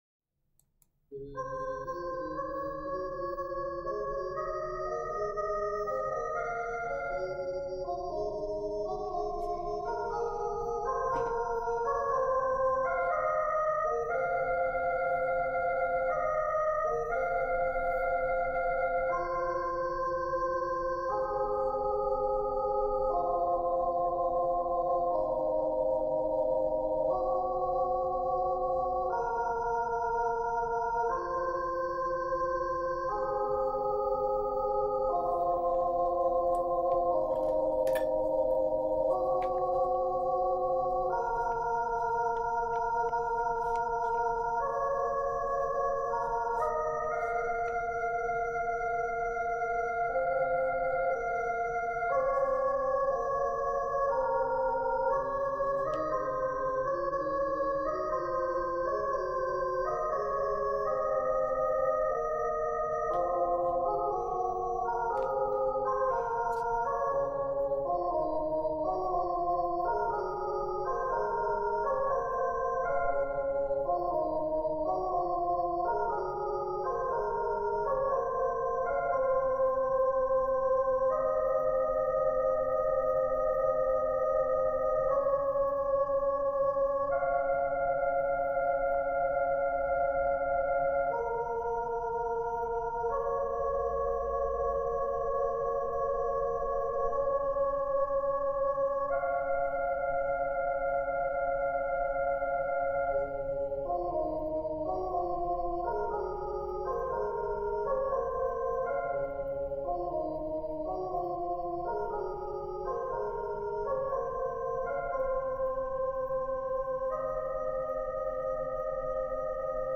subsubtitle = "bass; F clef on 4th line,     soprano; C clef on 1th line."
\key cis\major \time 3/2 \sopranoOne }
\set Staff.midiInstrument = "pad 4 (choir)" \unfoldRepeats \sopranoOne }